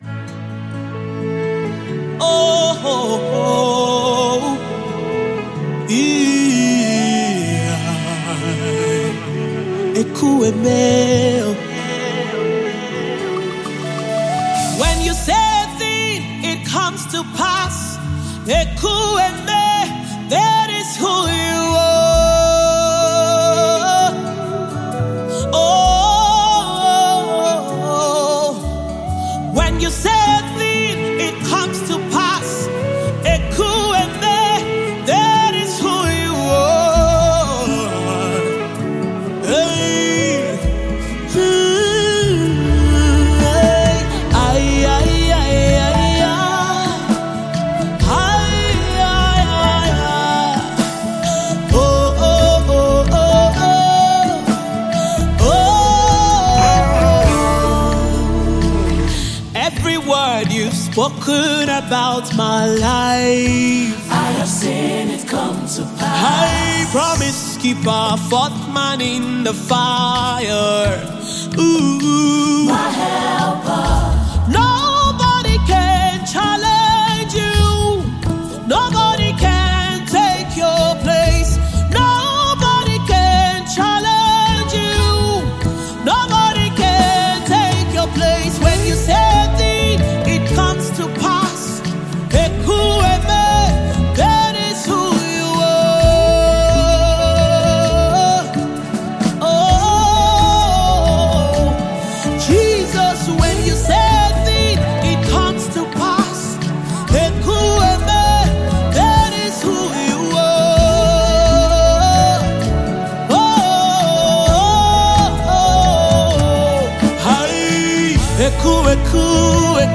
a soul-stirring gospel project with 10 powerful tracks
With her strong voice and heartfelt lyrics